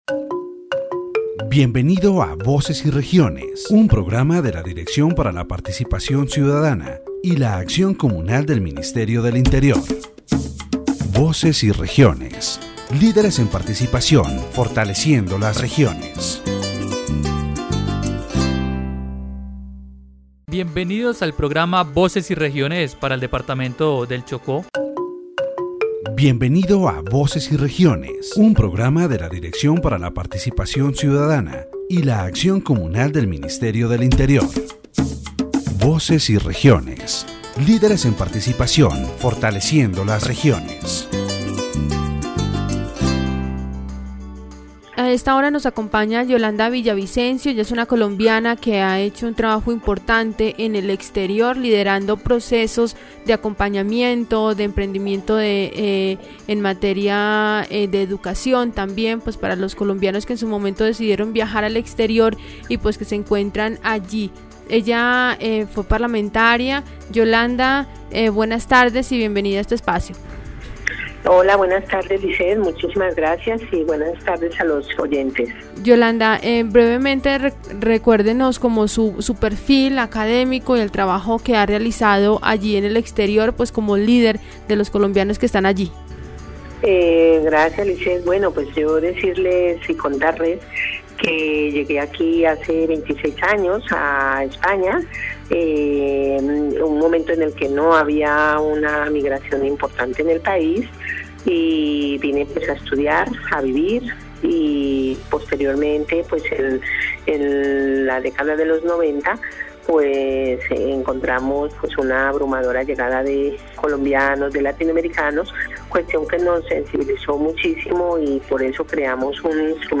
In this radio program, Yolanda Villavicencio, a Colombian prominent abroad, shares her experience as a leader of Colombian migrants.